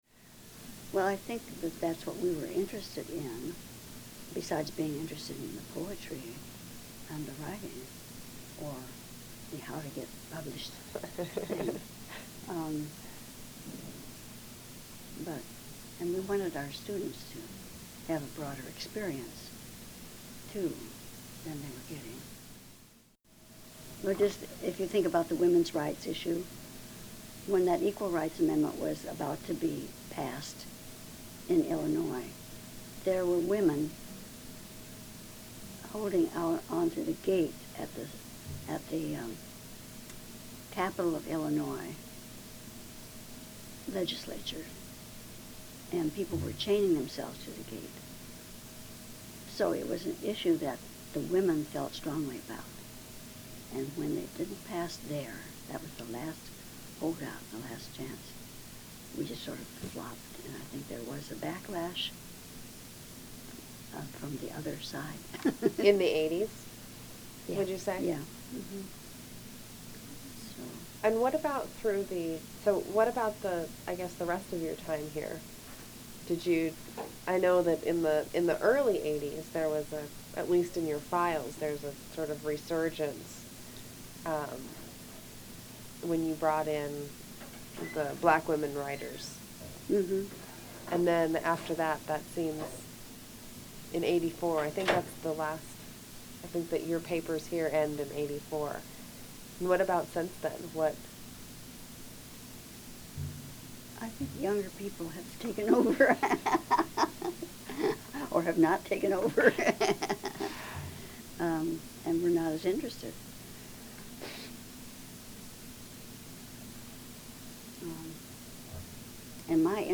Oral History
Location: Lane Community College Archives, Eugene, Oregon